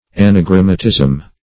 Search Result for " anagrammatism" : The Collaborative International Dictionary of English v.0.48: Anagrammatism \An`a*gram"ma*tism\, n. [Gr.
anagrammatism.mp3